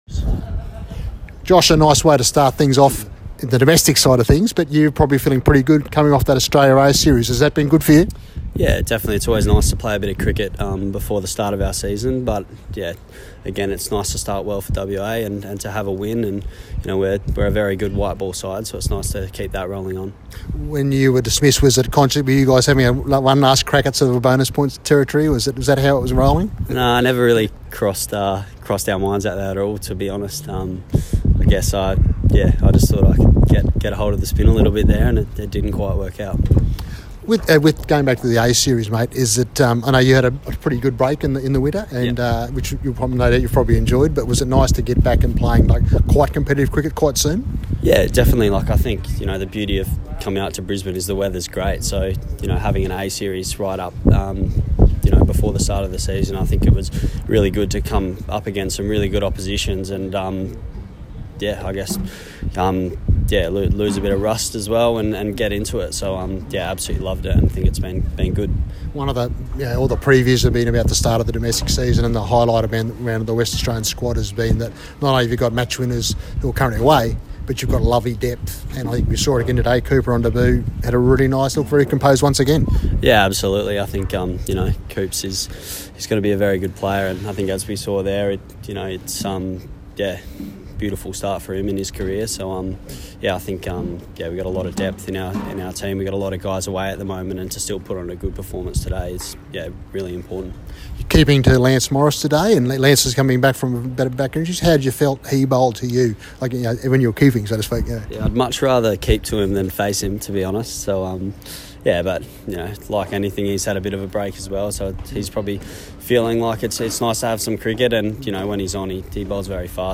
Marsh One Day Cup Queensland vs Western Australia, WA win; interview with player of the match Josh Philippe.